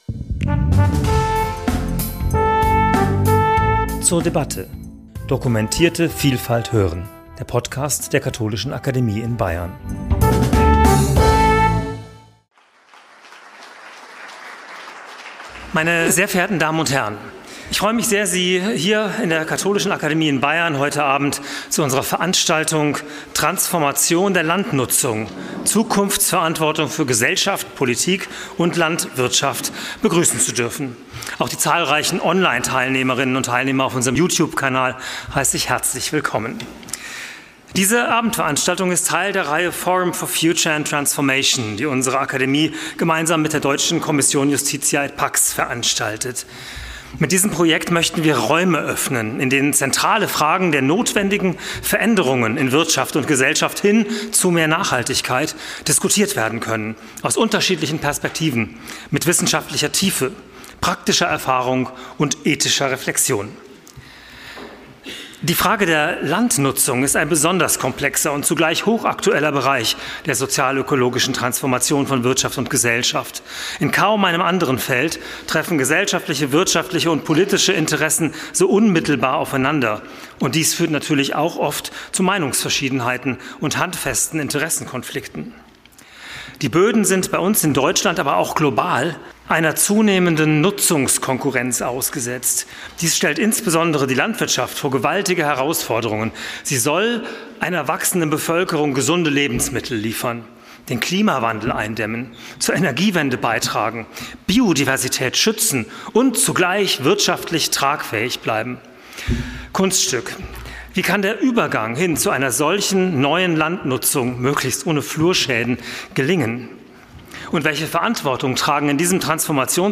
Unser Podiumsgespräch brachte Expertinnen und Experten aus Wissenschaft, Praxis und Verbänden zusammen, um unterschiedliche Sichtweisen und Perspektiven einzubeziehen.